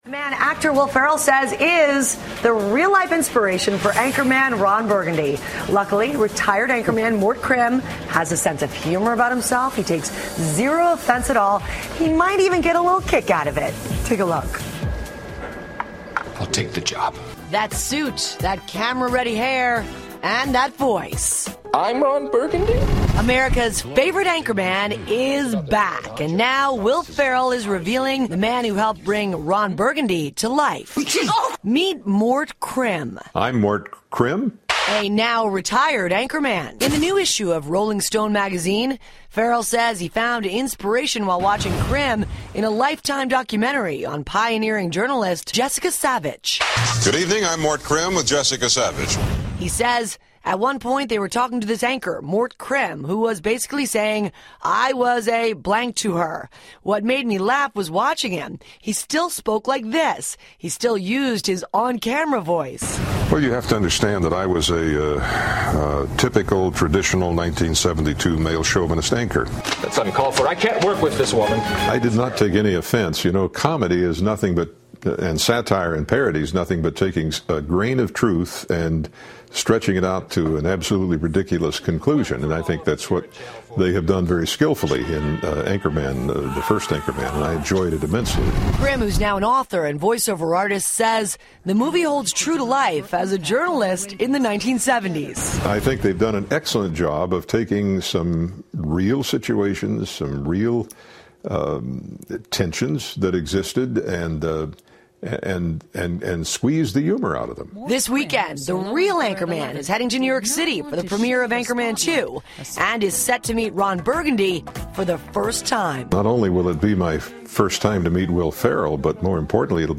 访谈录 2013-12-19&12-21 喜剧天才威尔·法瑞尔 听力文件下载—在线英语听力室